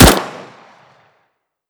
Index of /server/sound/weapons/dod_c96
cz75_01.wav